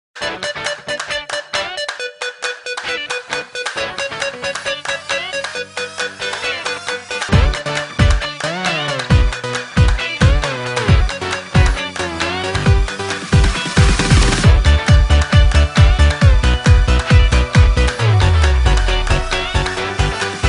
File Type : Latest bollywood ringtone